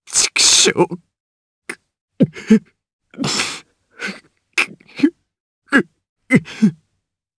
Kibera-Vox_Sad_jp.wav